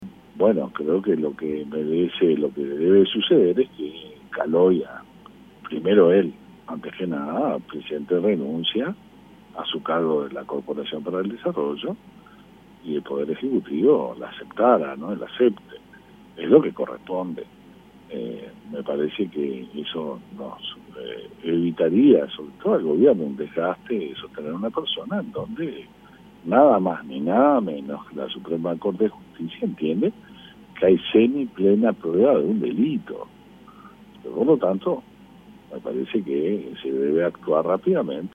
"La Suprema Corte de Justicia ha encontrado que hay semi plena prueba de un delito", dijo Heber en diálogo con El Espectador, recordando la advertencia que hizo el PN al oficialismo cuando se votó por mayoría simple la venia de Calloia.